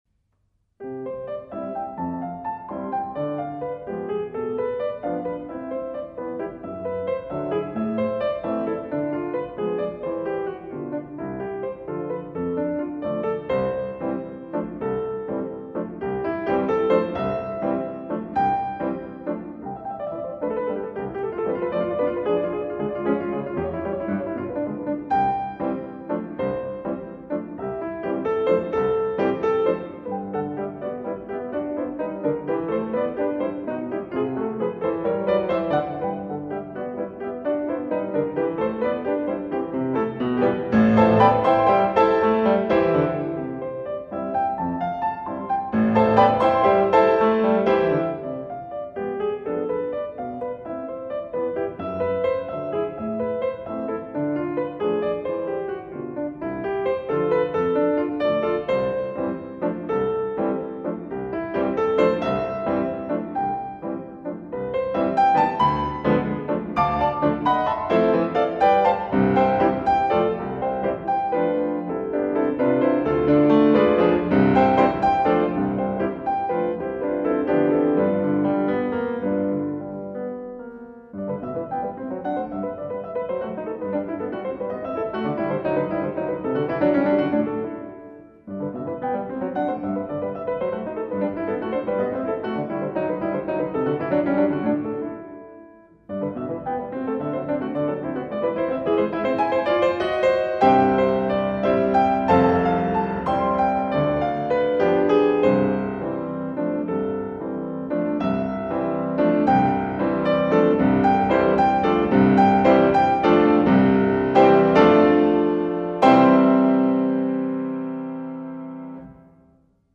Klavier
Instrument: Steinway & Sons D
Aufnahme: Barocksaal Rostock 2016